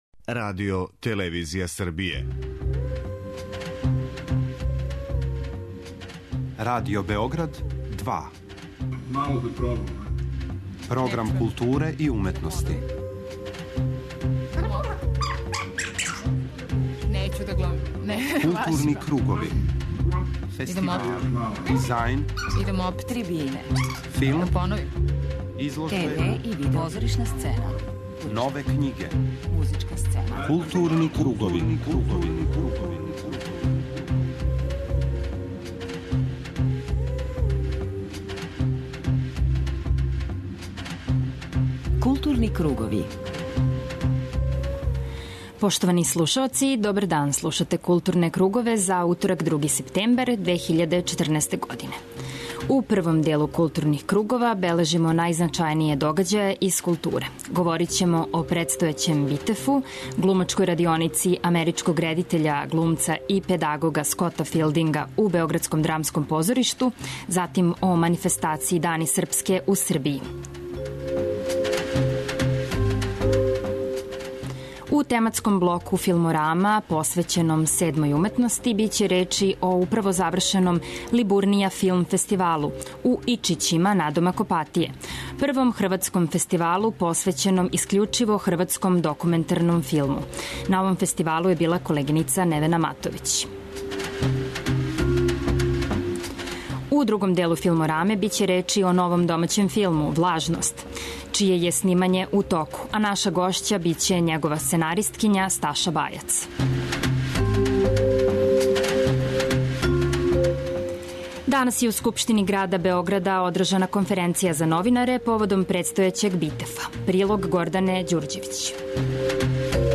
преузми : 52.37 MB Културни кругови Autor: Група аутора Централна културно-уметничка емисија Радио Београда 2.